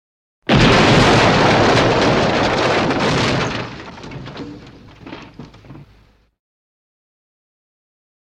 Звук, когда динамит разрушил все здание